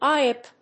アイ‐エー‐ビーピー